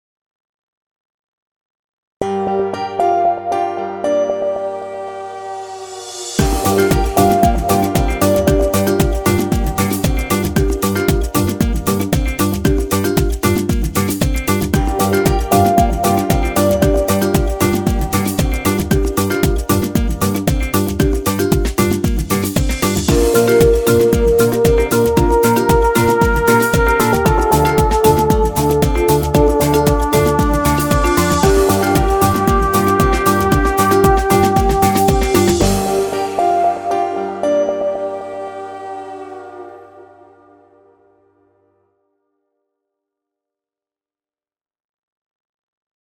Television and Film Titles, Logos & Idents